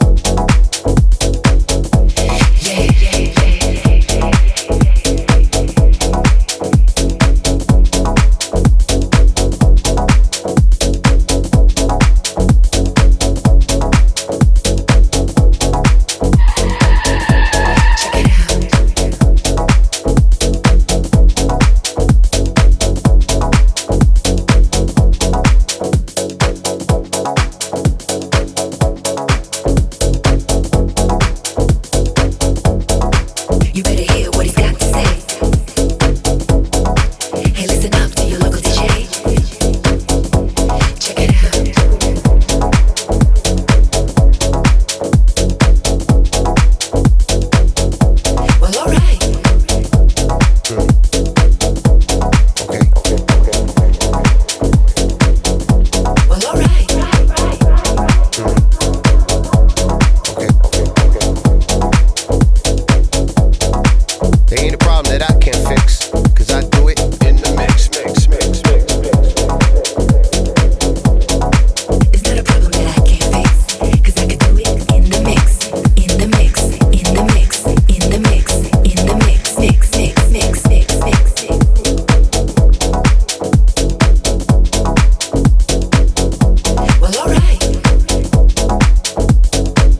irresistible House Music energy